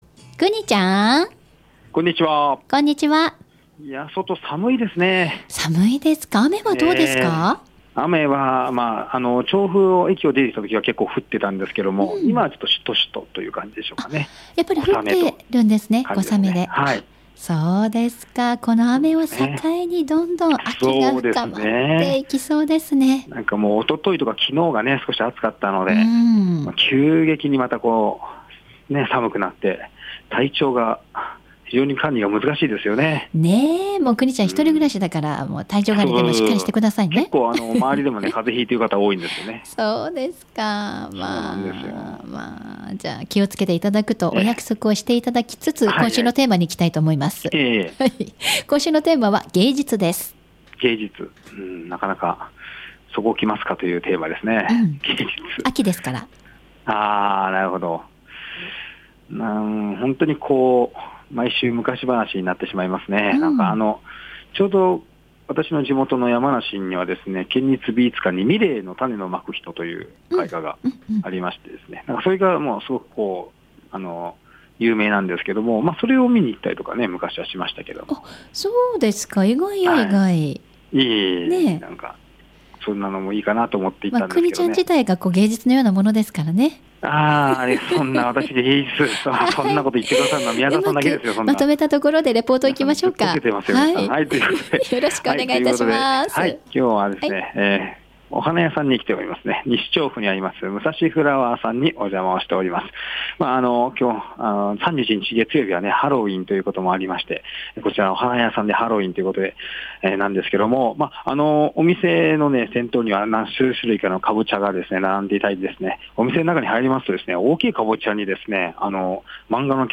さて今週は西調布に出没。
③~⑥は放送中に作業いただきましたので、画像はありません。